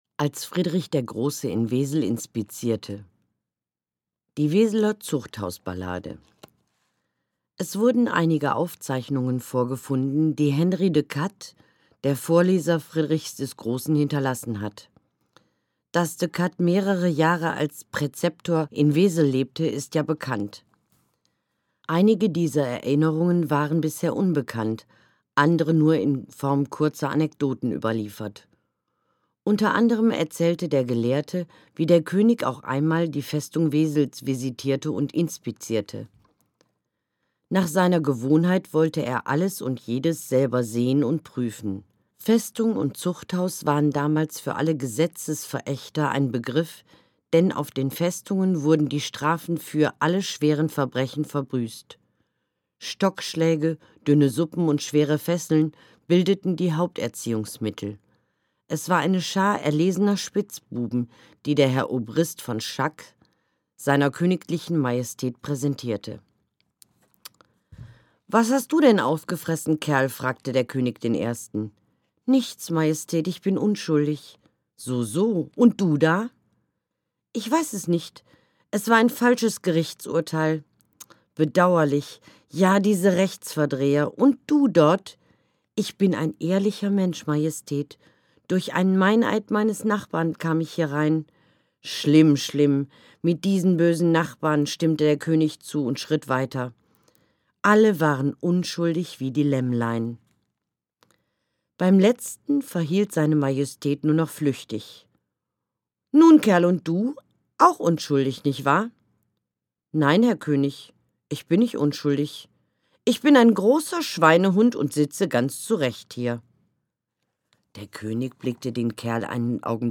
Gelesen von